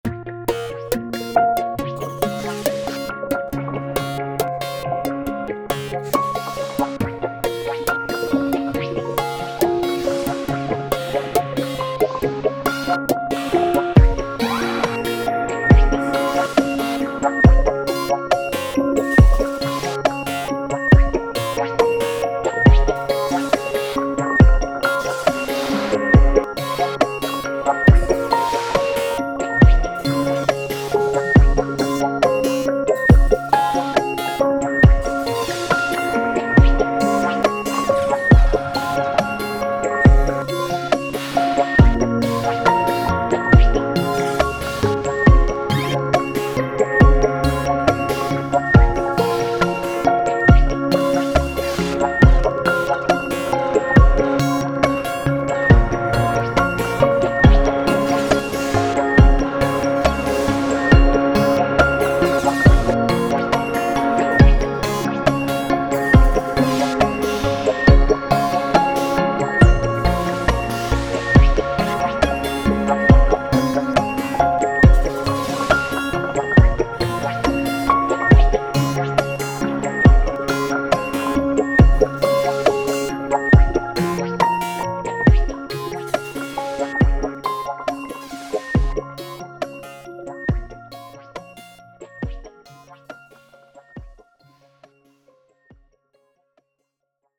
電子音とピアノの組み合わせでニュートラルかつ無色透明なデジタル世界っぽさを出そうとしている。